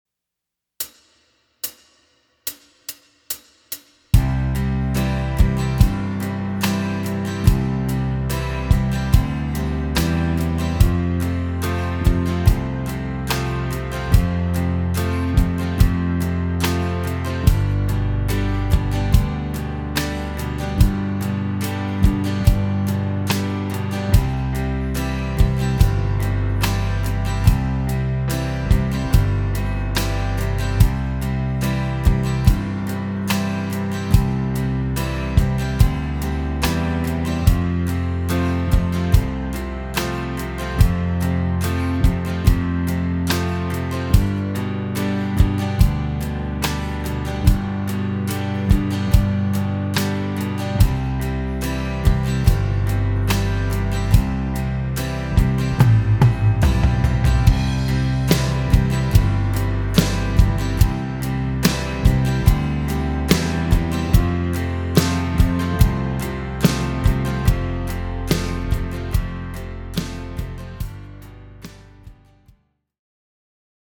Track Sample, C Major: